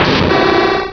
pokeemerald / sound / direct_sound_samples / cries / houndoom.aif